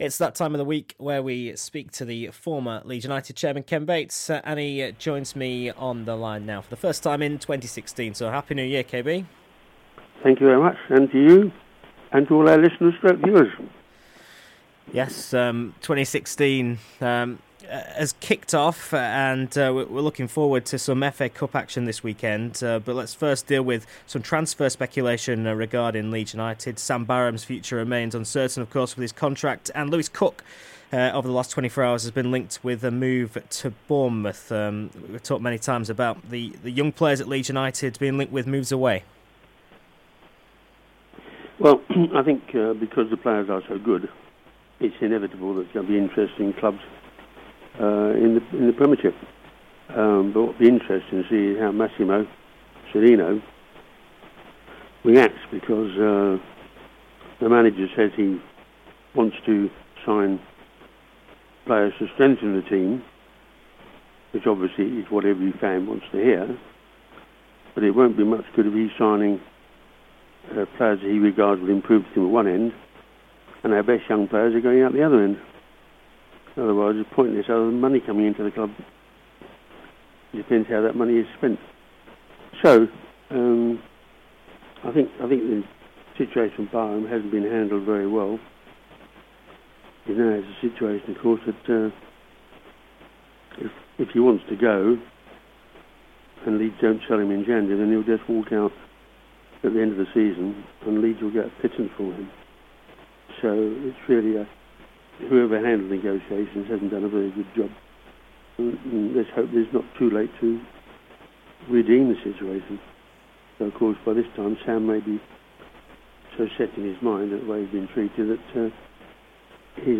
Ken Bates interview 07/01/2016